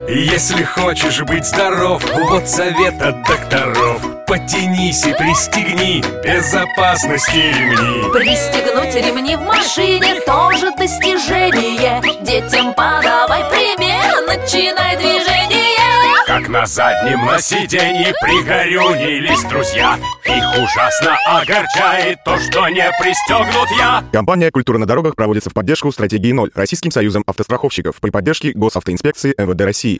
Частушки_ч.1